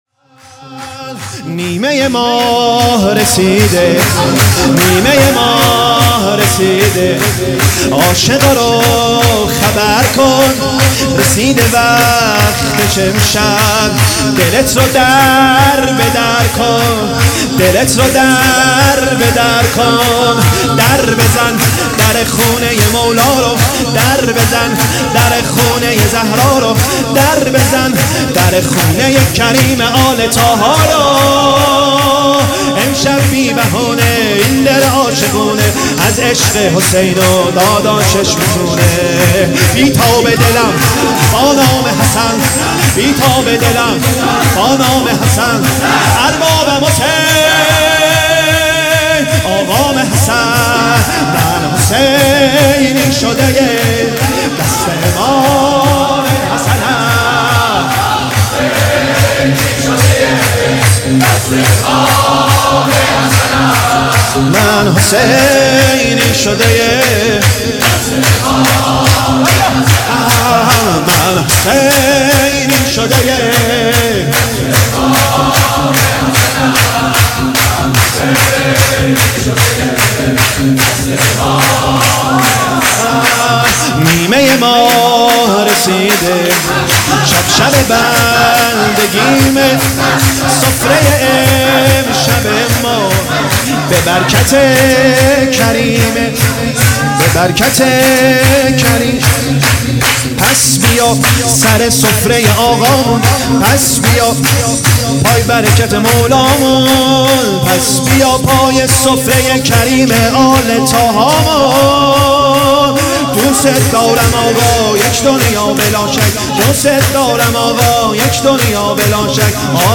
سرود | نیمه ماه رسیده
ولادت امام حسن(ع)